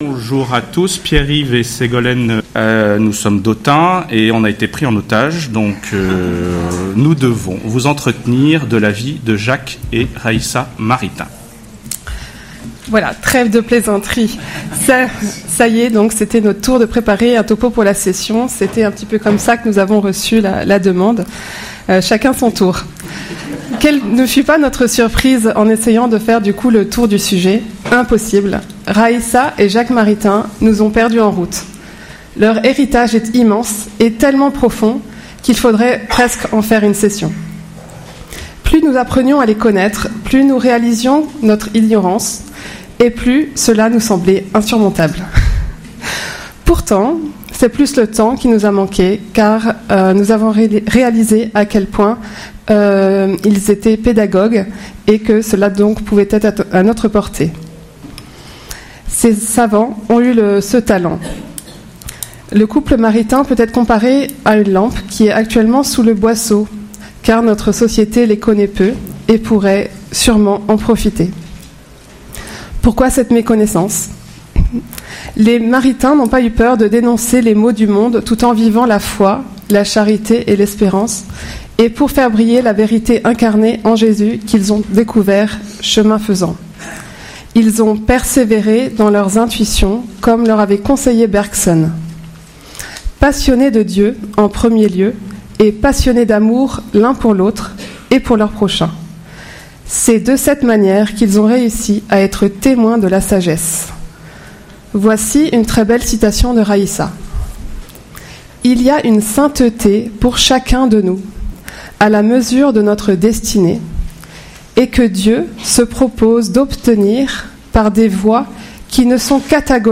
Enseignement/Témoignage